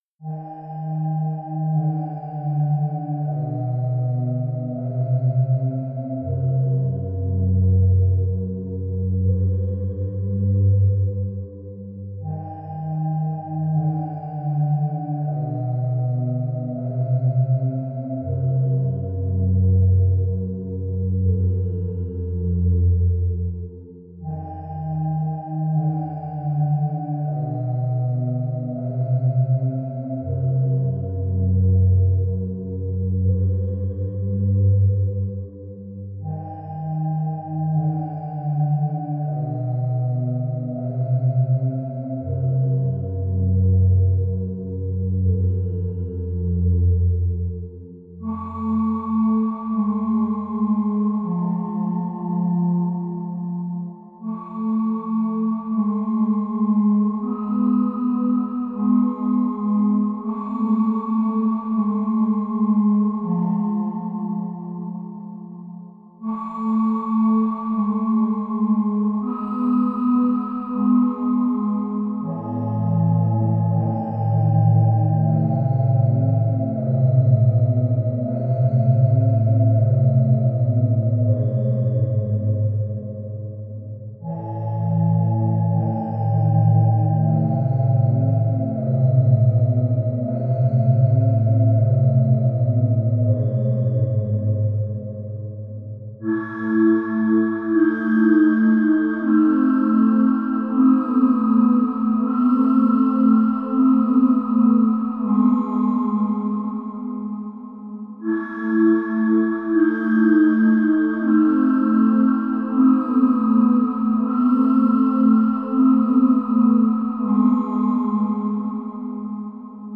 暗くて不安なホラー曲です。【BPM80】